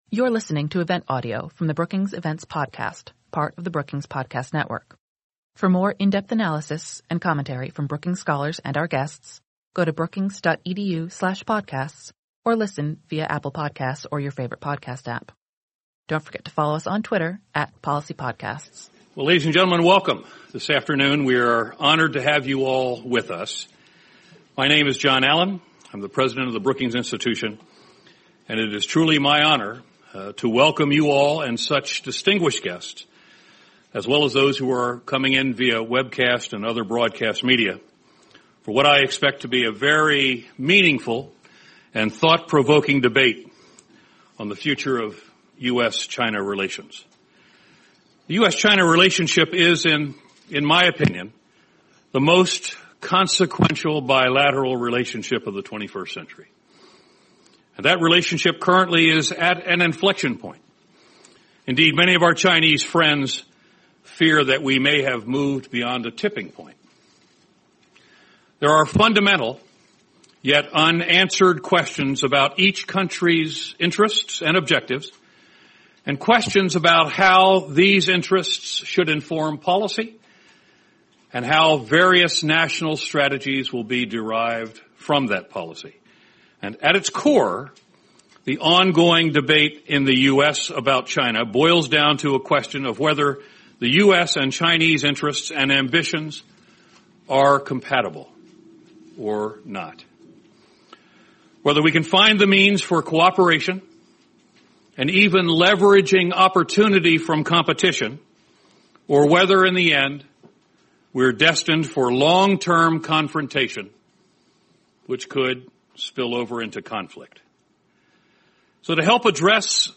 星期二董云裳出席华盛顿智库布鲁金斯研究所以“美中长期利益是否根本不相容”为题举行的辩论。